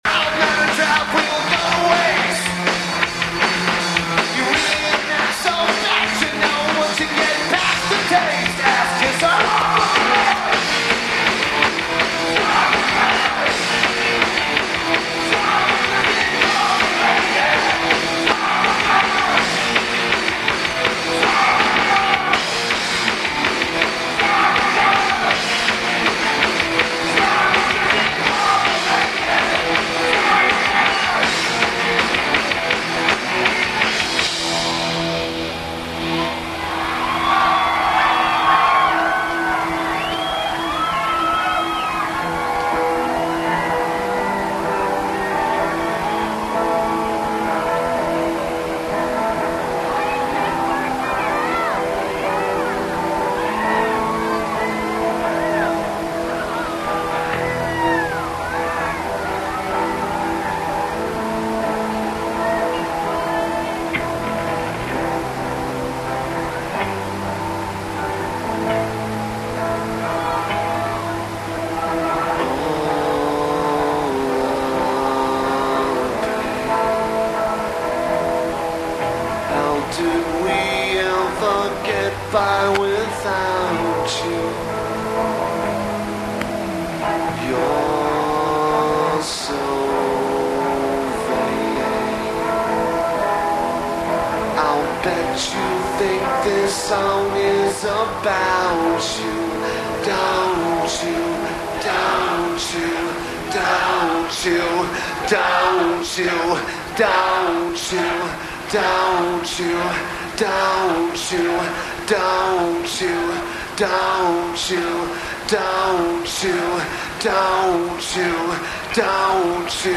Lineage: Audio - IEM (ICOM IC-R5 + Sony MZ-N707)